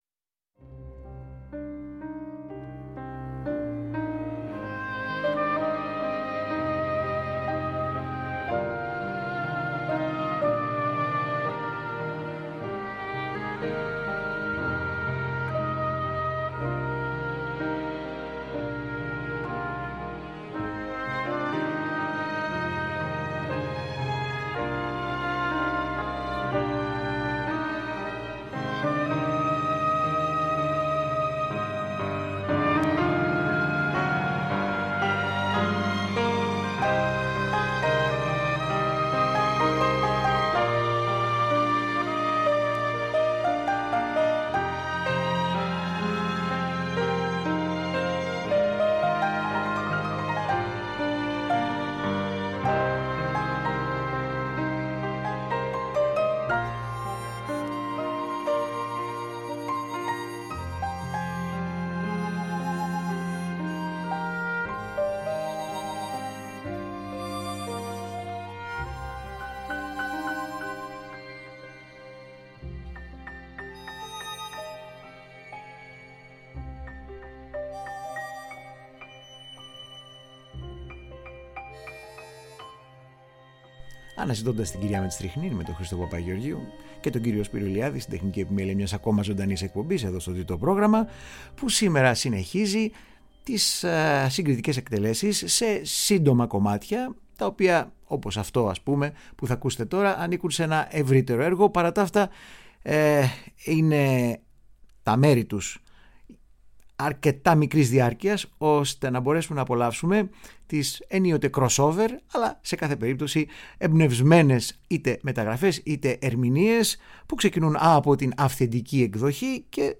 Γνωστά κομμάτια σε απρόσμενες εκδοχές και ερμηνείες.